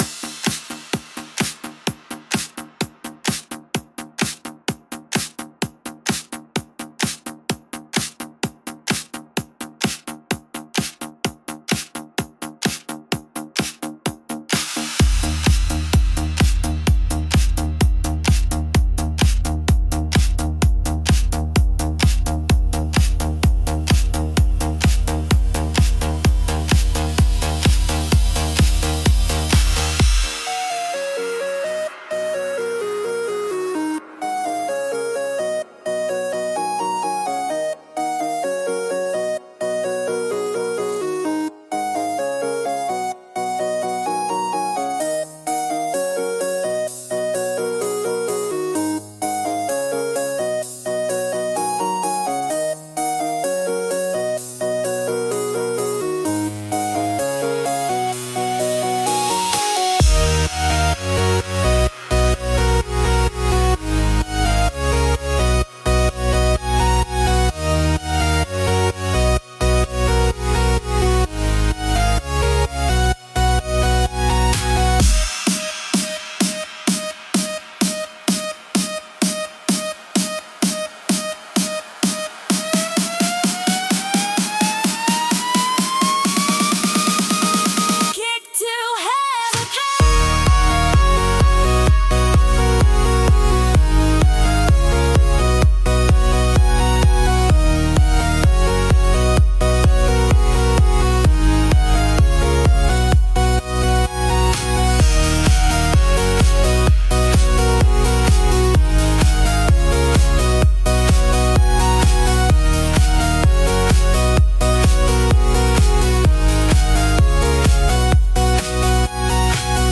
F# Major – 128 BPM
Electronic
Pop